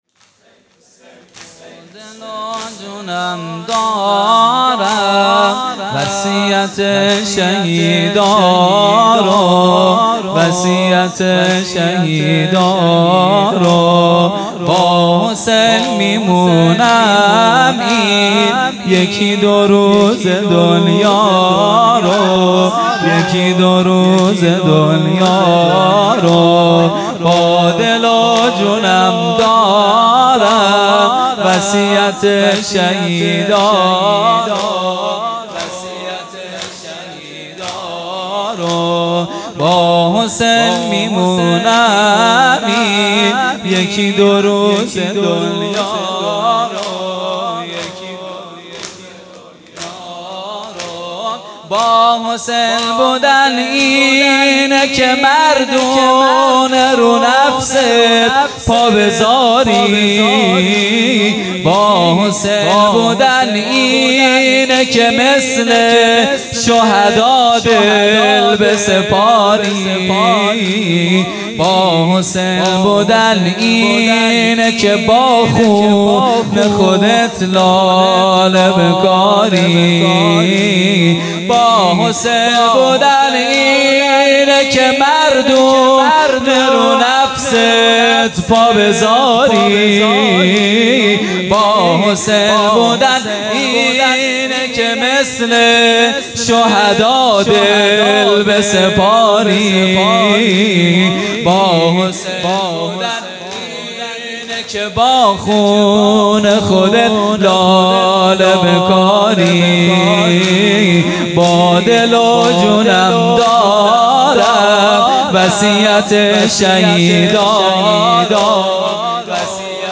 خیمه گاه - هیئت بچه های فاطمه (س) - زمینه | با دل و جونم دارم، وصیت شهیدا رو